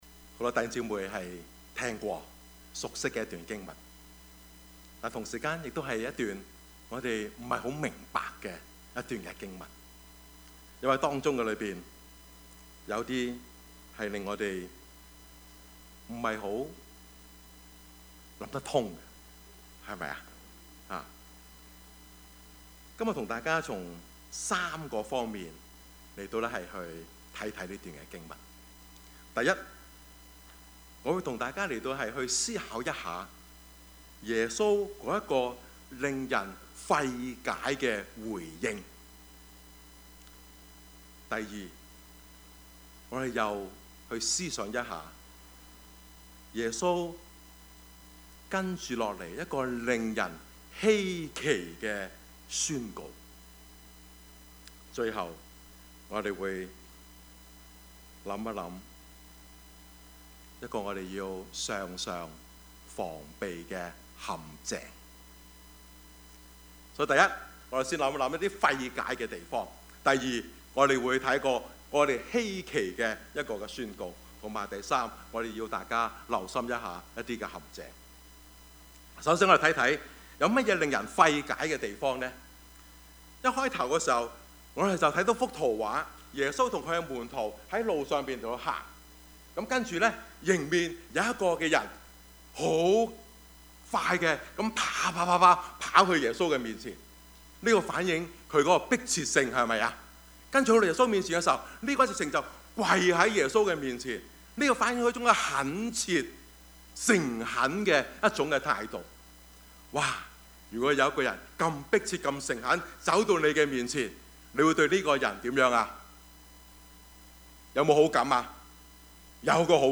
Service Type: 主日崇拜
Topics: 主日證道 « 可以不可以?